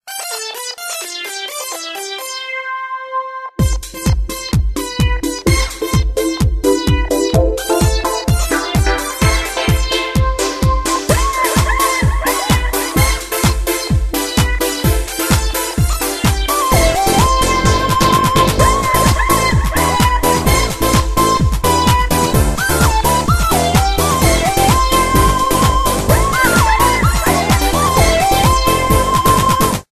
В стиле Хаус